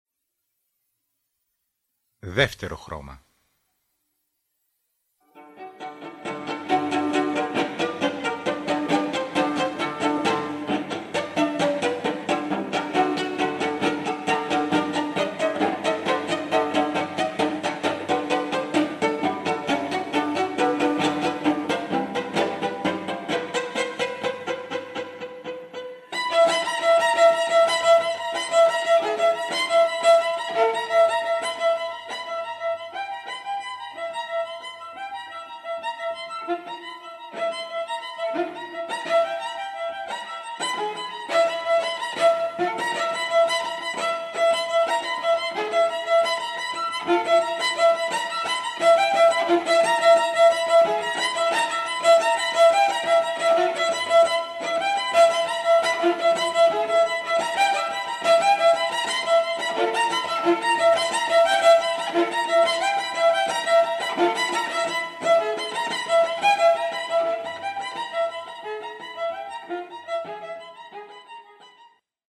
για  Βιολοντσέλο